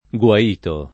guaito [ gU a & to ] s. m.